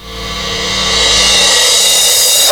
REVERSCYM2-L.wav